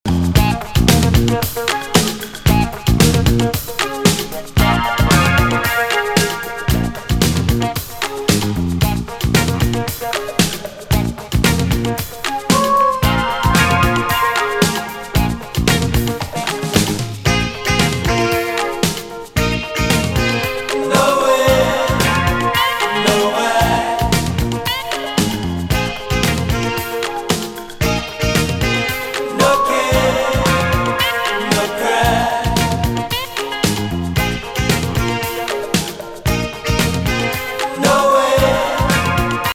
大人のバレアリック御伽噺！